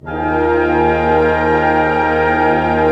Index of /90_sSampleCDs/Roland LCDP08 Symphony Orchestra/ORC_ChordCluster/ORC_Pentatonic